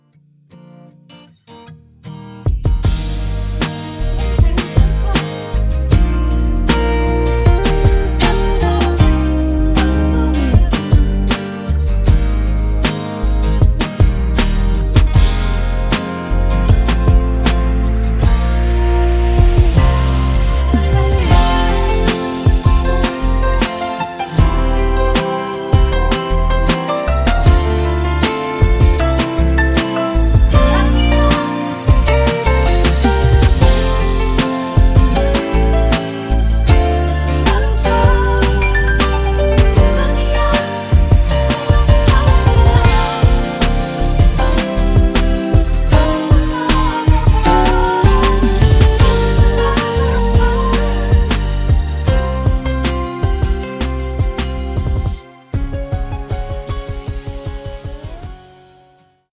음정 -1키 3:07
장르 가요 구분 Voice MR
보이스 MR은 가이드 보컬이 포함되어 있어 유용합니다.